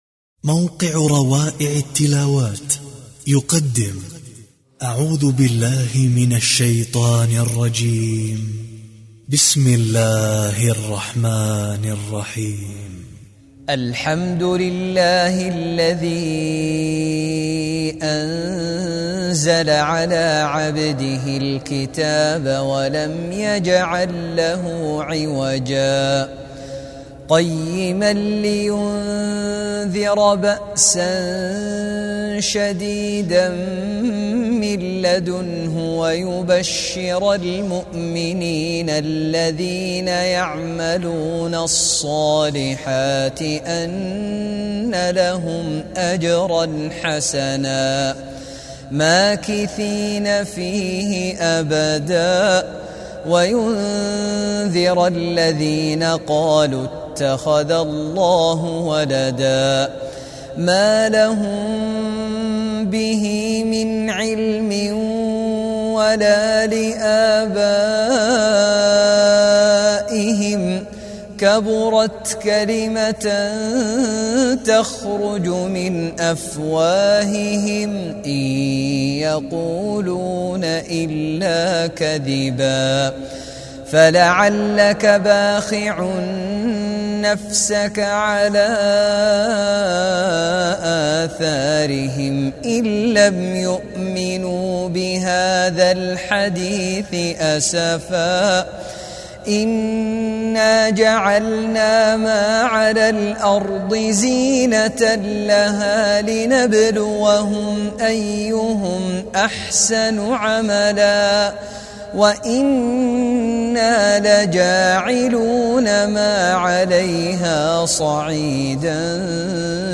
سورة الكهف بصوت تلاوة مشتركة لقر اء جدة